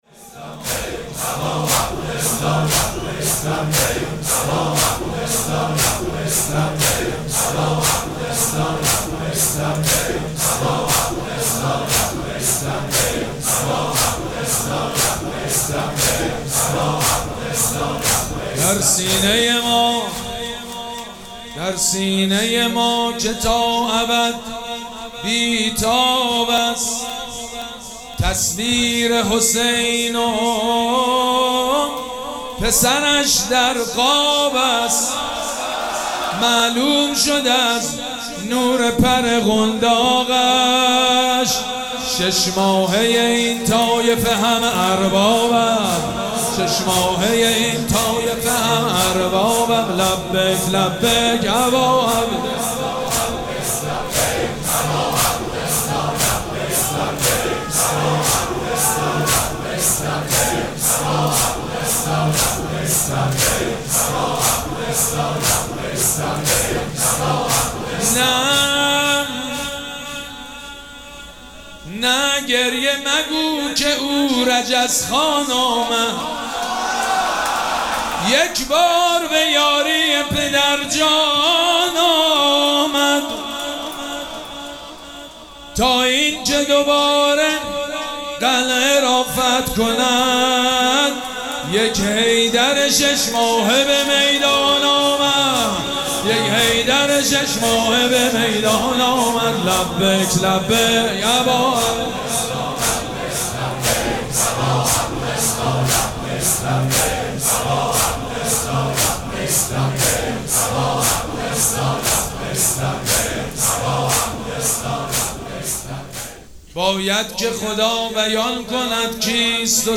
مراسم عزاداری شب هفتم محرم الحرام ۱۴۴۷
حاج سید مجید بنی فاطمه